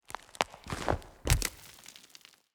SFX_Root_Attack_Mavka.wav